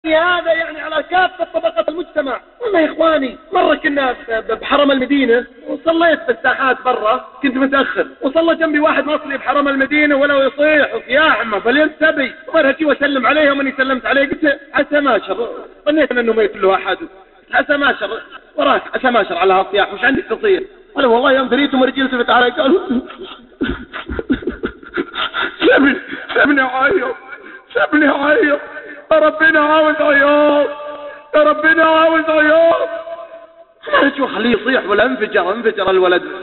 من هو هذا الملقي :